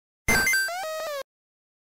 4. Игровой звук тетриса